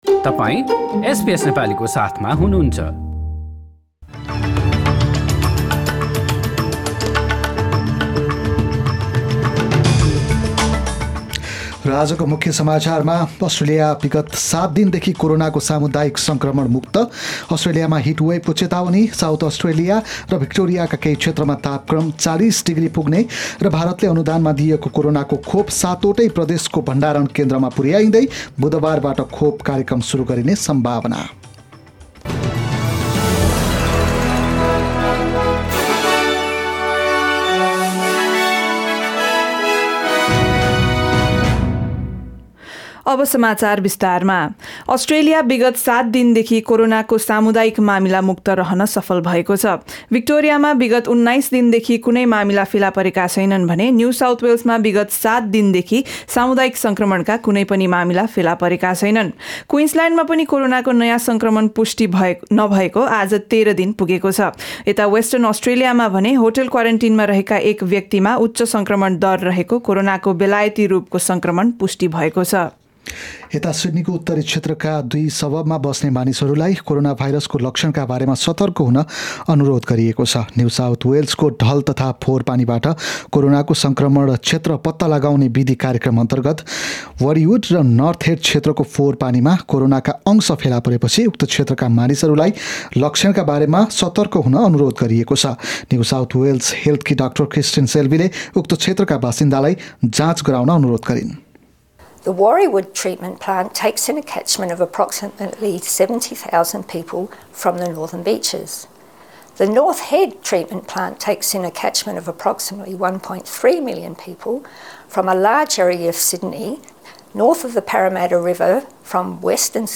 SBS Nepali Australia News: Sunday 24 January 2021
Listen to the latest news headlines in Australia from SBS Nepali radio.